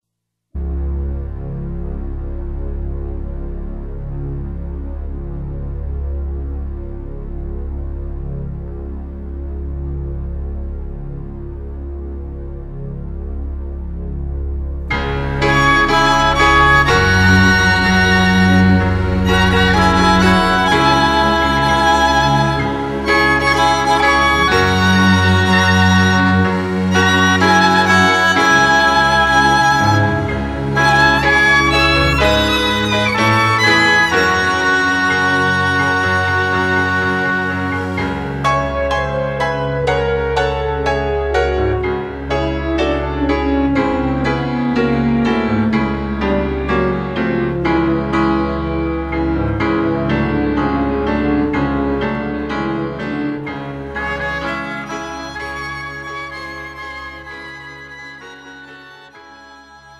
음정 (-1키)
장르 뮤지컬 구분